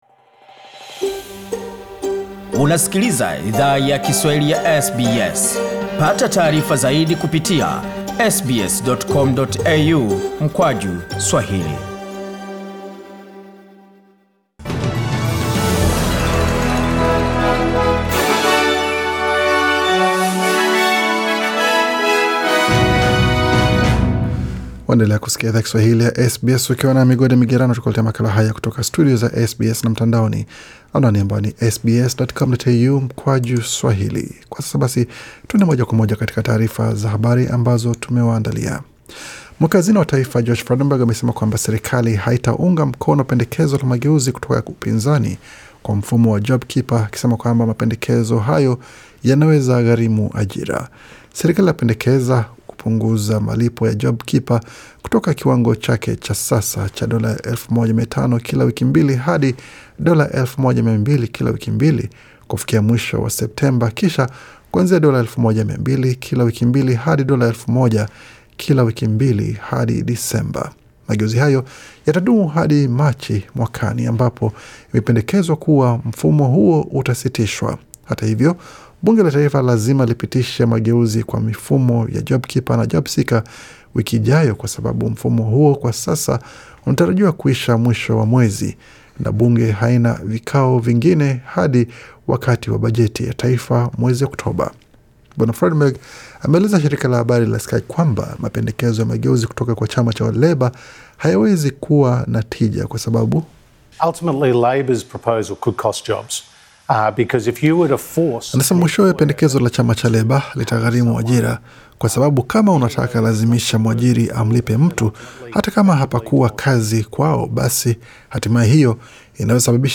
Taarifa ya habari 30 Agosti 2020